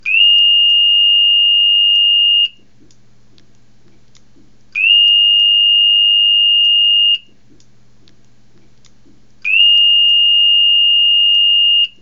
Soft-evacuation.mp3